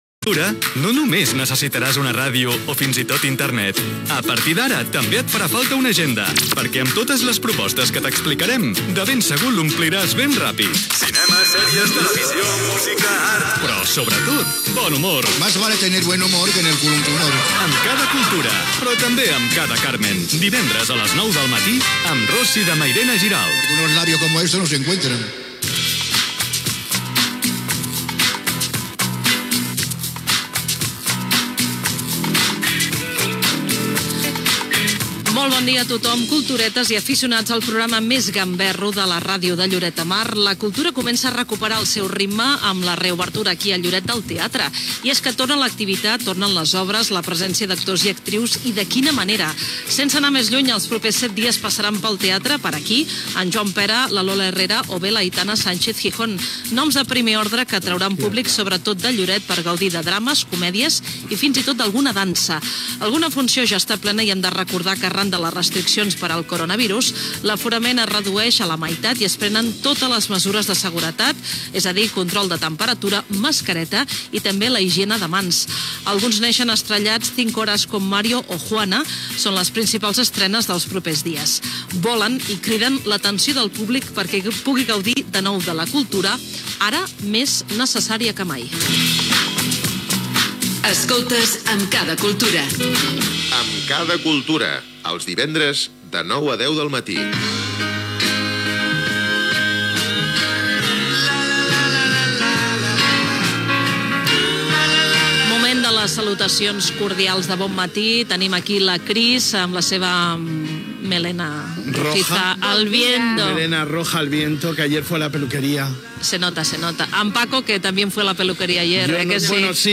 Cultura
Programa transmès per Facebook Live.